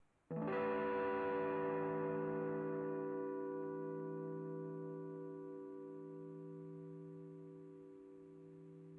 描述：模拟声音，吉他操作
Tag: 吉他 合成器